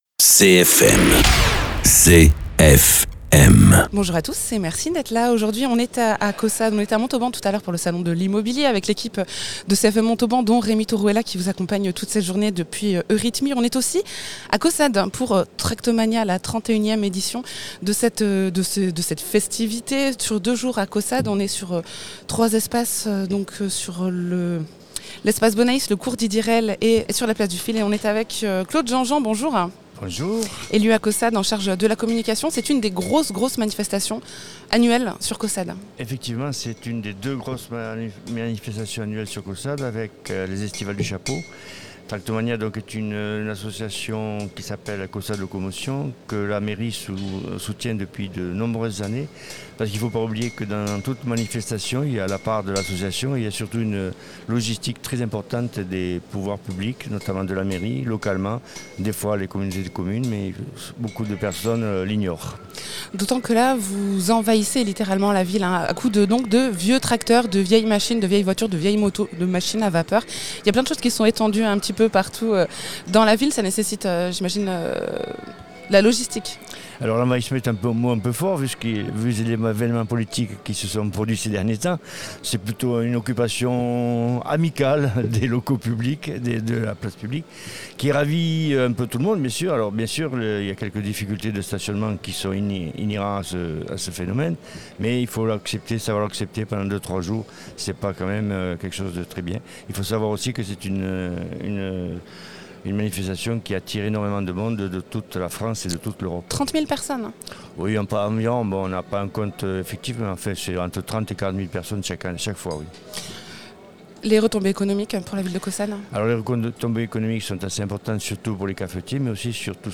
Témoignages et entretiens diffusés en direct depuis Tractomania à Caussade le samedi.
Interviews